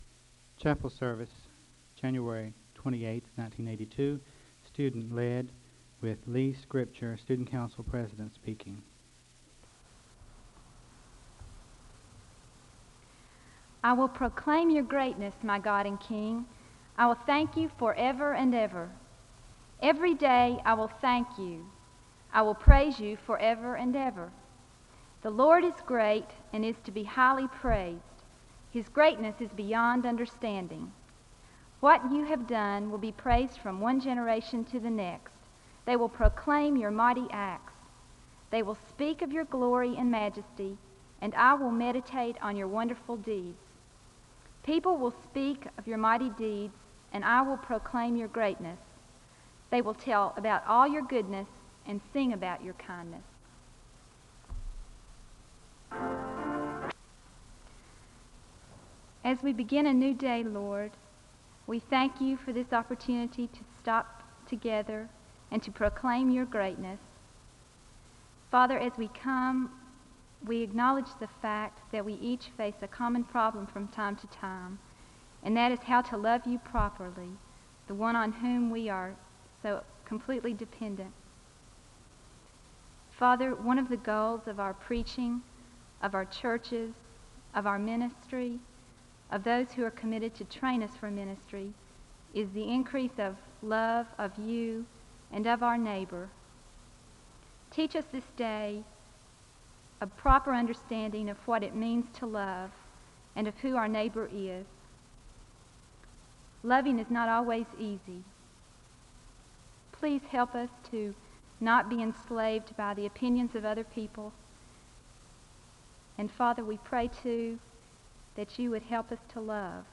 SEBTS Chapel - Student Council January 28, 1982
Download .mp3 Description The service begins with a reading from the litany and a word of prayer (00:00-02:24).
He leads in a corporate confession (10:59-11:44).